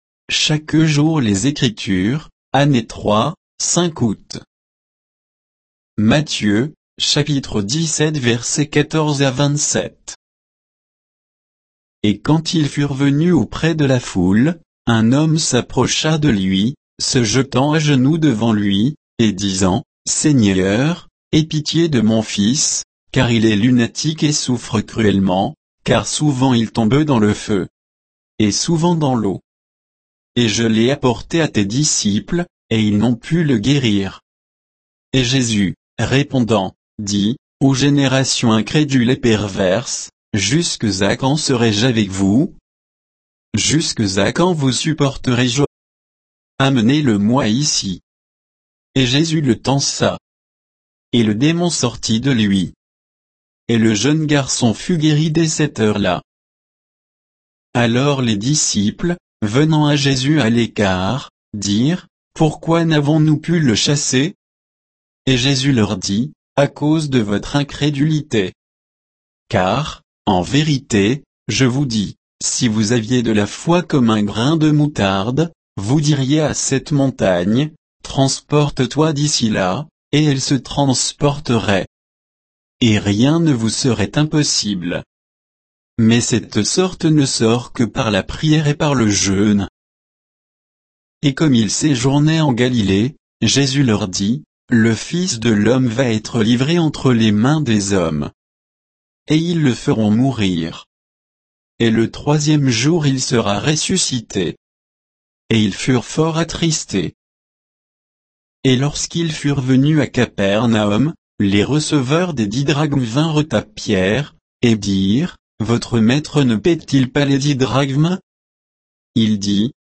Méditation quoditienne de Chaque jour les Écritures sur Matthieu 17